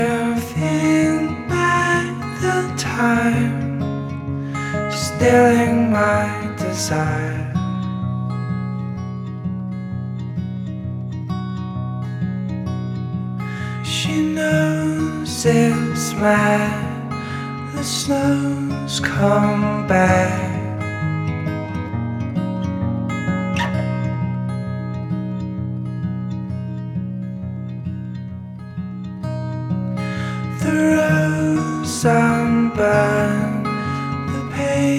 # Ambient